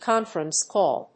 cónference càll